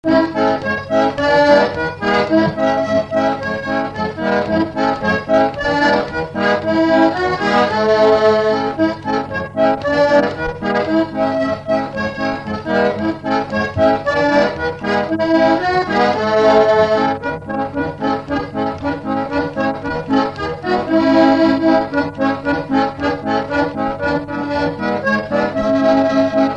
Instrumental
Pièce musicale inédite